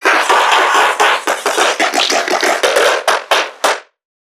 NPC_Creatures_Vocalisations_Infected [58].wav